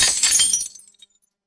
Glass Breaking
A pane of glass shattering on impact with sharp crack and cascading shards
glass-breaking.mp3